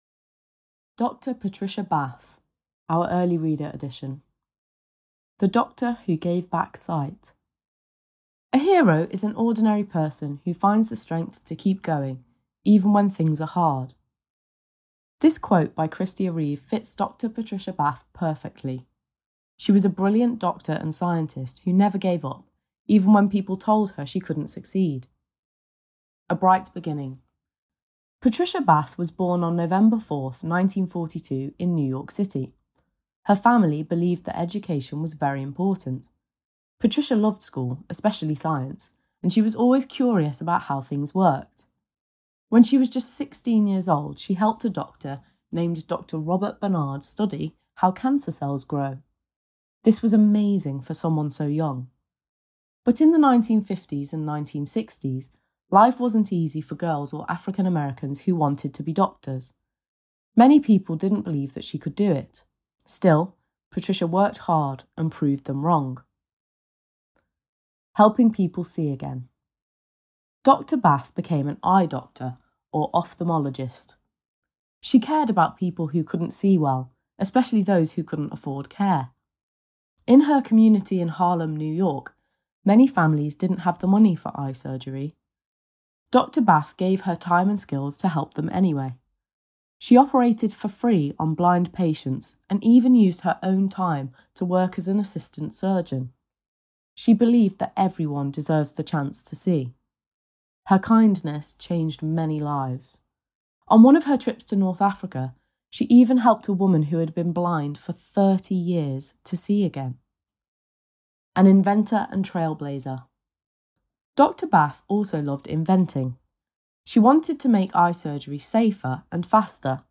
Listen to this early reader story about Dr. Patricia Bath.